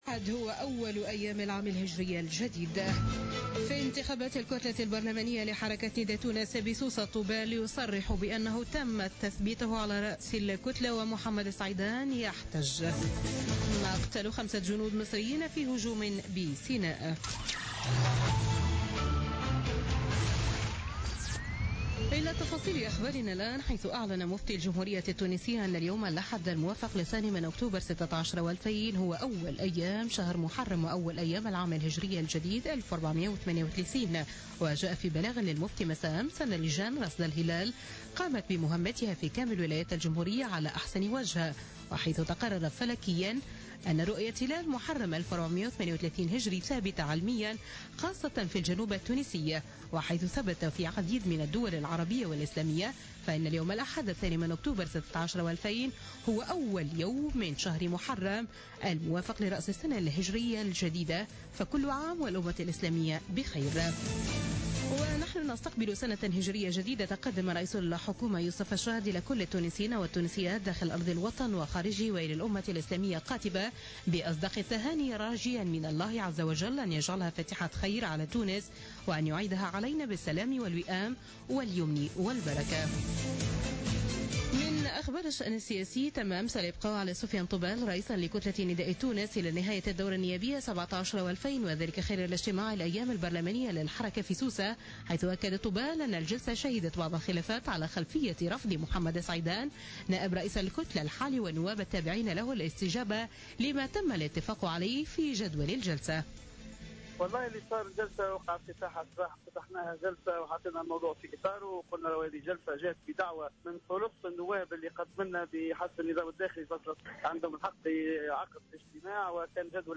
نشرة أخبار منتصف الليل ليوم الأحد 2 أكتوبر 2016